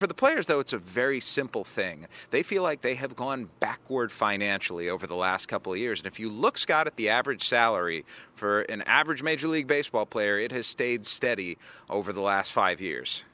Here’s what Jeff Passan, ESPN baseball columnist, had to say about the player’s current financial situation.